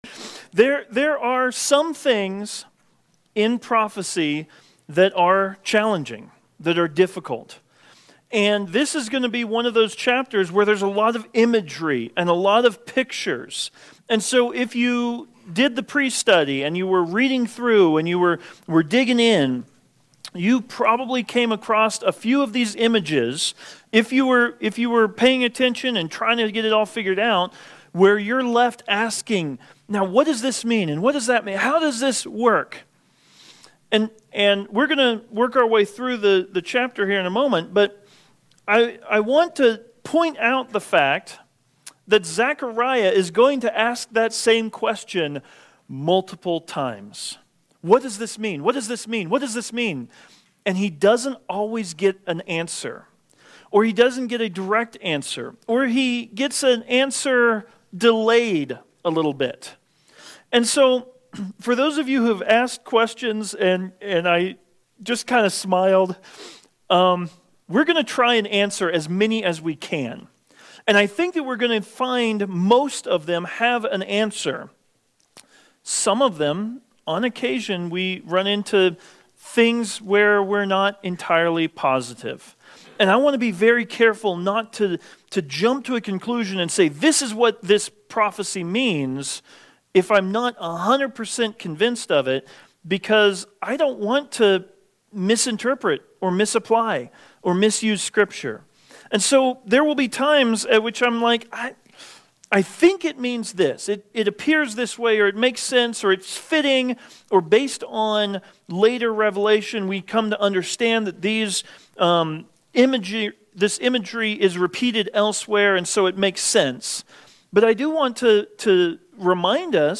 The newest sermons from Cascade Bible Church on SermonAudio.